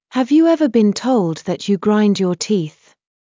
ﾊﾌﾞ ﾕｰ ｴｳﾞｧｰ ﾋﾞｰﾝ ﾄｰﾙﾄﾞ ｻﾞｯﾄ ﾕｰ ｸﾞﾗｲﾝﾄﾞ ﾕｱ ﾃｨｰｽ